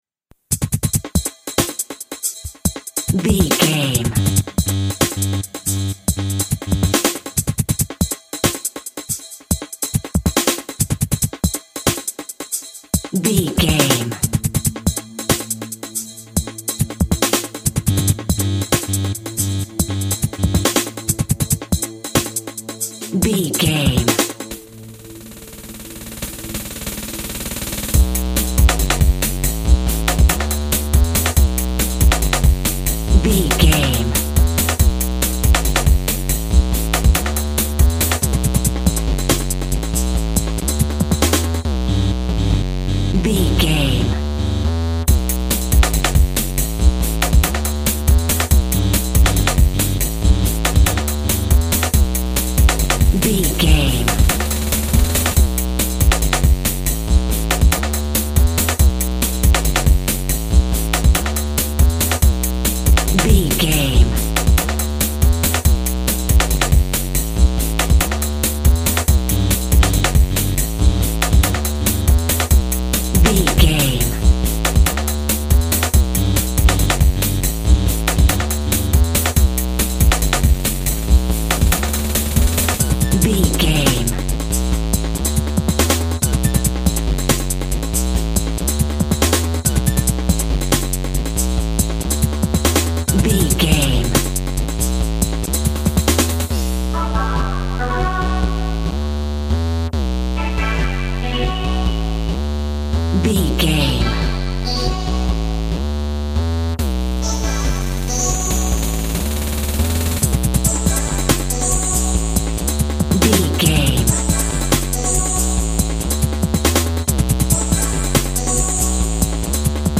Breakbeat Techno.
In-crescendo
Aeolian/Minor
futuristic
hypnotic
industrial
driving
energetic
frantic
dark
electronic
synth lead
synth bass